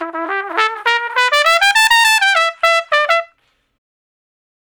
084 Trump Shuffle (E) 06.wav